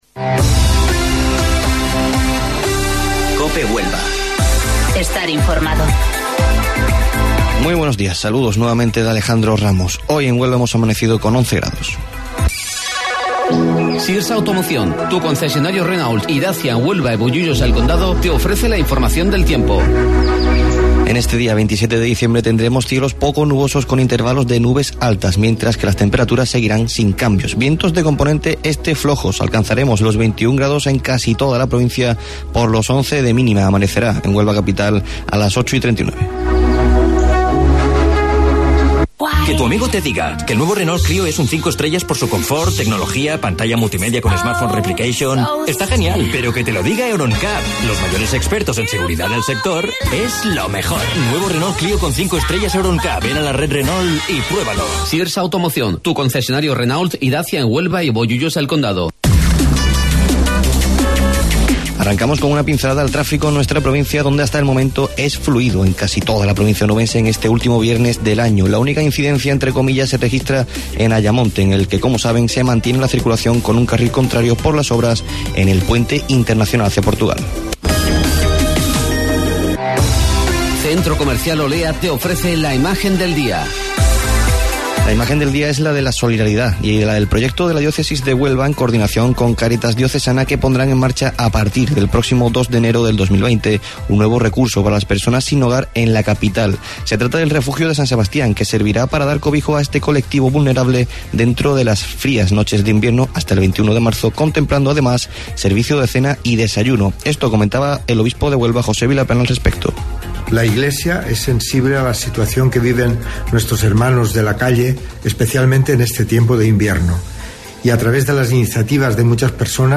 AUDIO: Informativo Local 08:25 del 27 de Diciembre